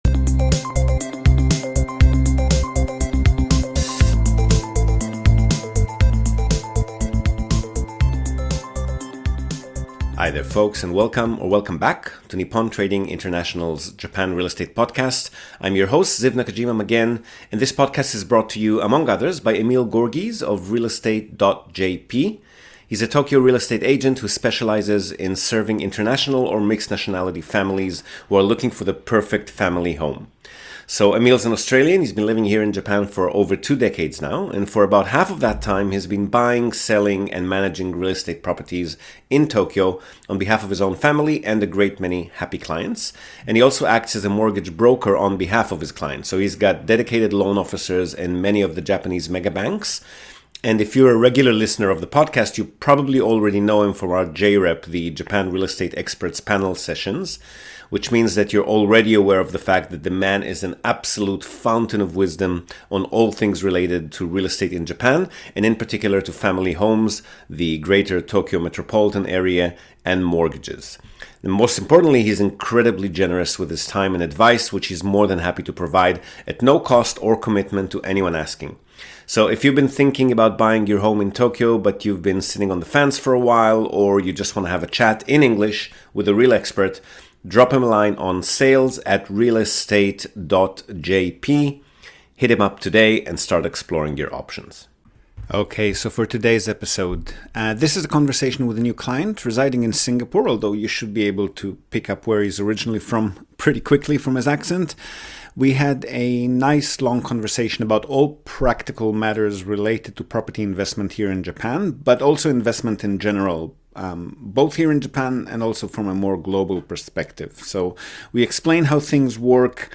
A conversation with a new client - we cover the differences between investing in Japan and in other countries, market fundamentals, asset classes, tenant profiles and tenancy leases, the purchase process, taxes, insurance, and much more!